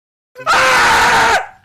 thdscream.mp3